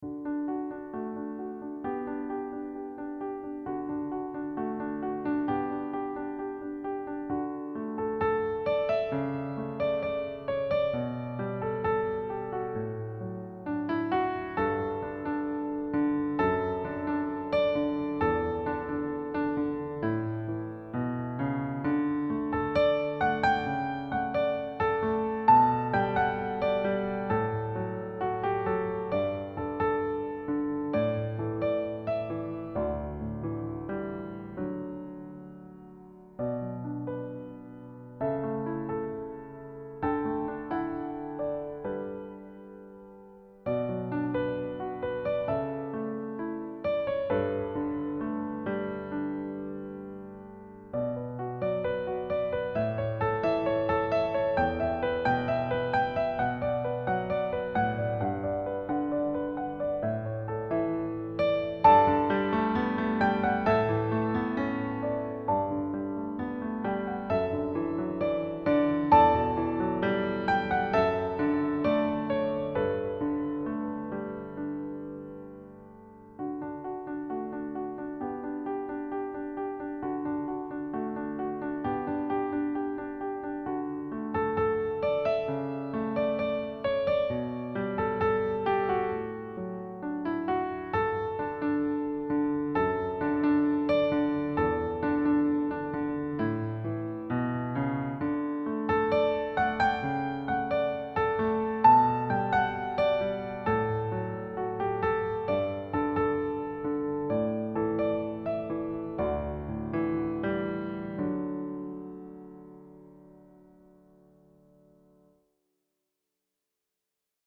• Key: D Major
• Time signature: 4/4
• Developing smooth, lyrical phrasing in a ballad style